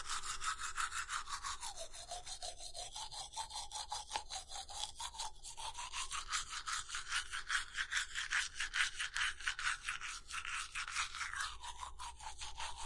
SFX图书馆类项目VS " 刷牙
描述：刷牙
标签： 齿 牙齿 牙刷
声道立体声